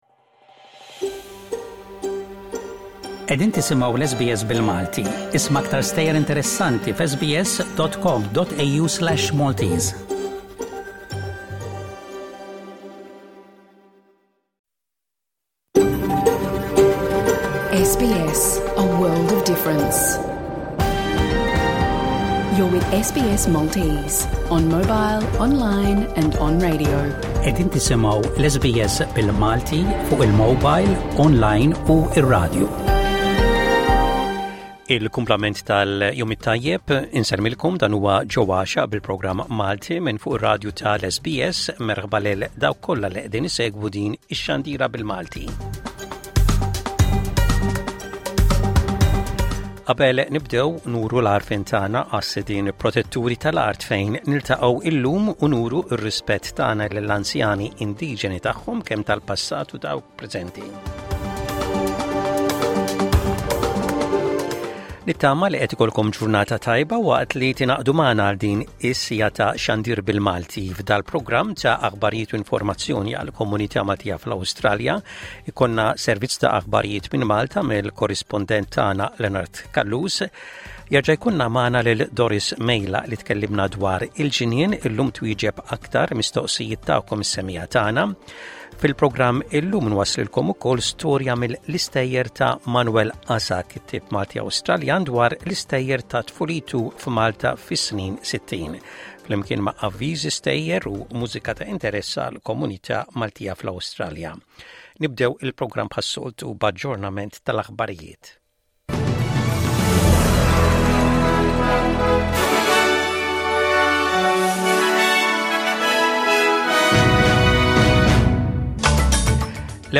l-aħbarijiet mill-Awstralja, servizz ta' aħbarijiet minn Malta mill-korrispondent